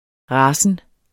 Udtale [ ˈʁɑːsən ]